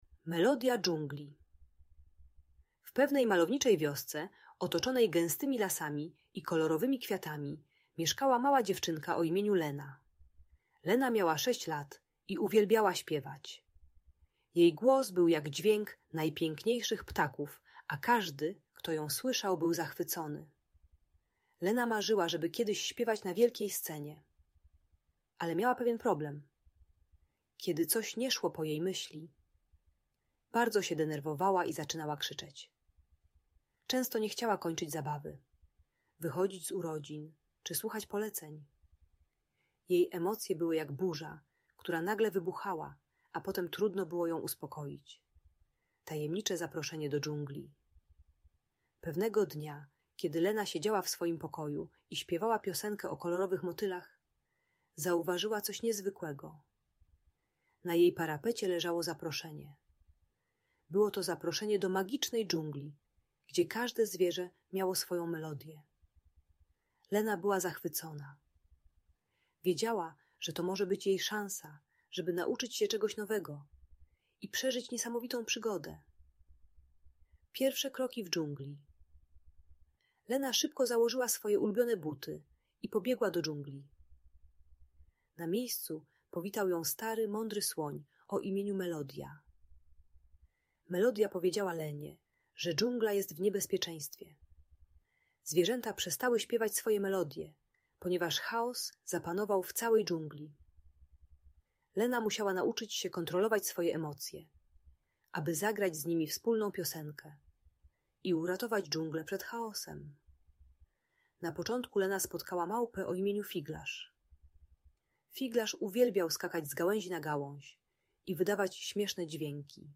Melodia Dżungli - Opowieść o Emocjach i Przygodach - Audiobajka dla dzieci